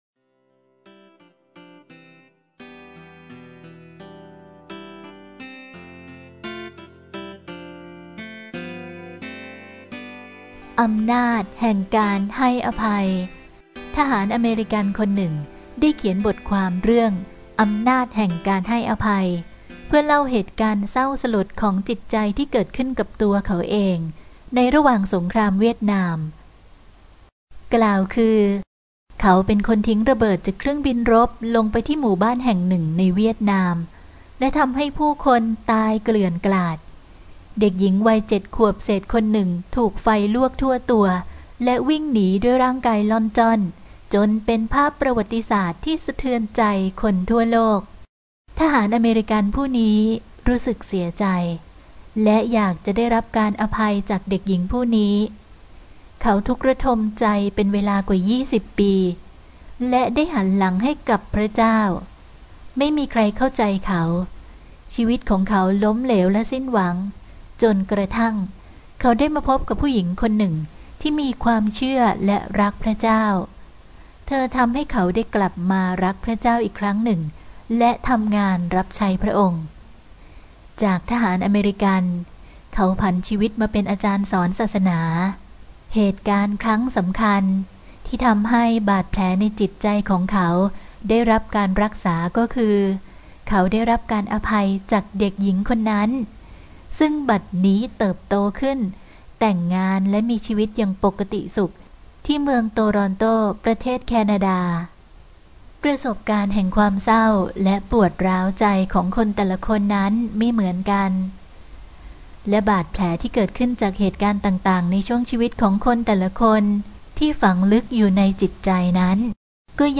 เสียงอ่านโดย